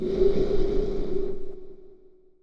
snd_whistlebreath_ch1.wav